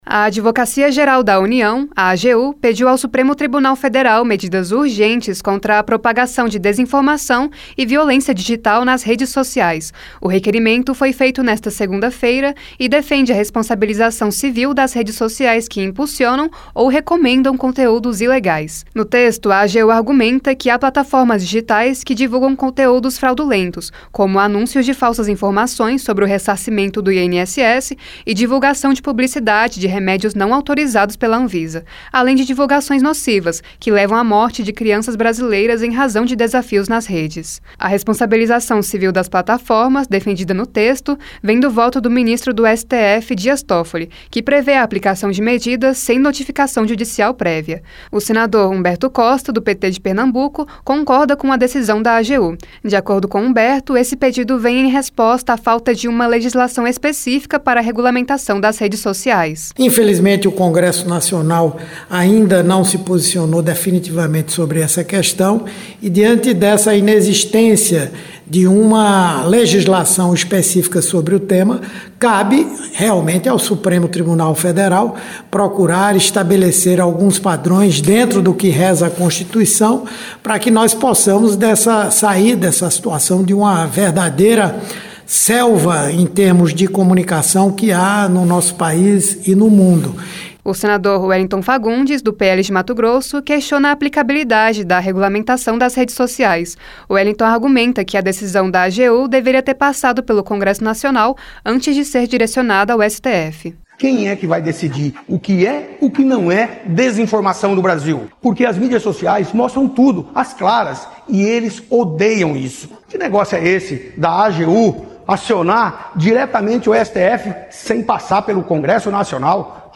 O senador Humberto Costa (PT-PE) concordou com o pedido e afirmou que é uma medida necessária. O senador Wellington Fagundes (PL-MT) argumenta que a decisão deveria ter passado pelo Congresso antes de seguir para o STF.